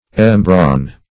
Search Result for " embrawn" : The Collaborative International Dictionary of English v.0.48: Embrawn \Em*brawn"\, v. t. To harden.